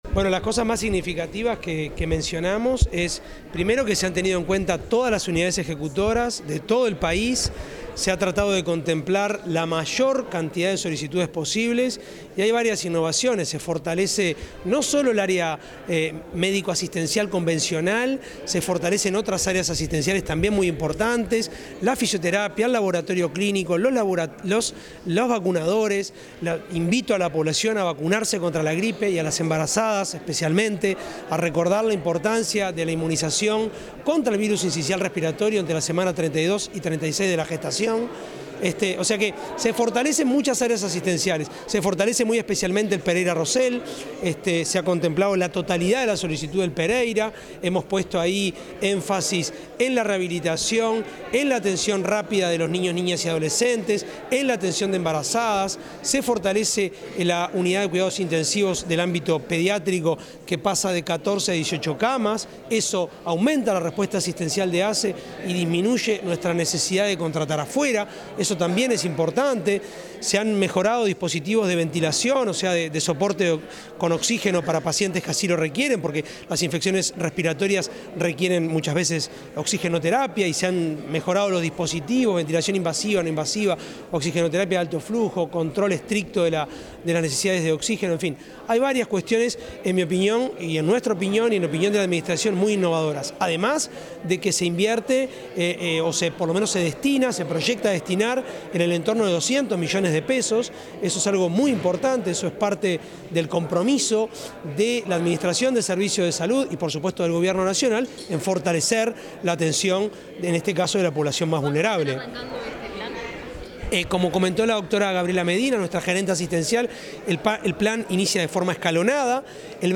El presidente de la Administración de los Servicios de Salud del Estado (ASSE), Álvaro Danza, efectuó declaraciones tras el lanzamiento del Plan